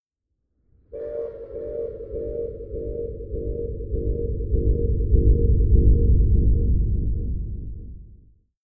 Horn.mp3